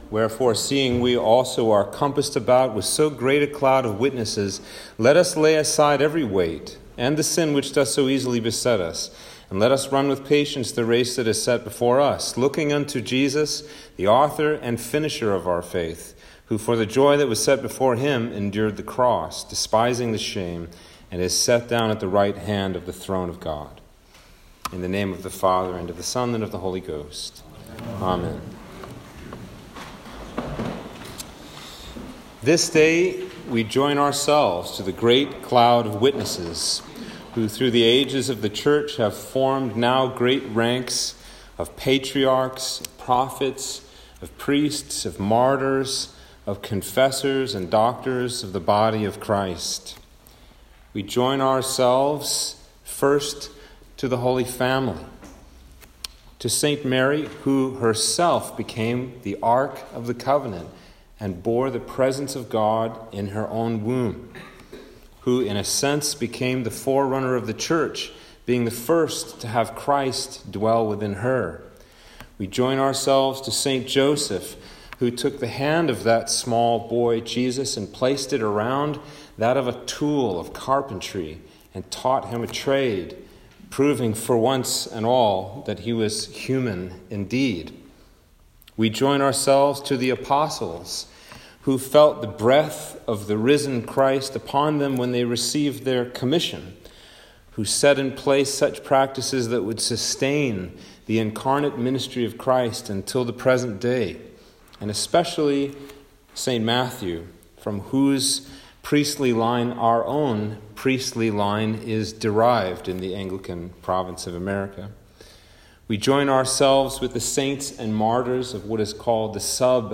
Sermon For All Saints - 2021